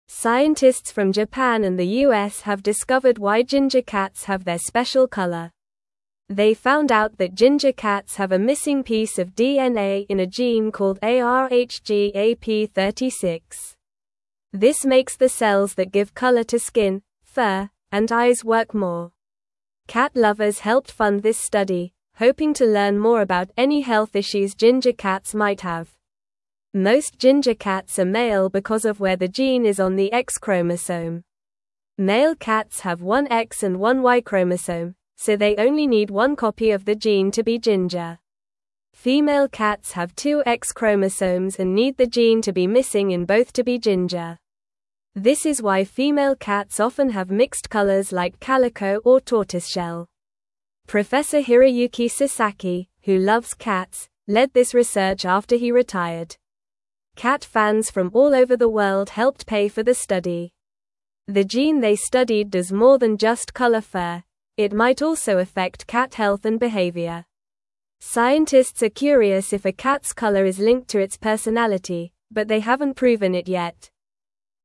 Normal
English-Newsroom-Lower-Intermediate-NORMAL-Reading-Why-Ginger-Cats-Are-Mostly-Boys-and-Orange.mp3